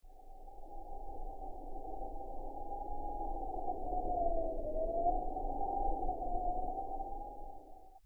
Gentle Wind 02
Gentle_wind_02.mp3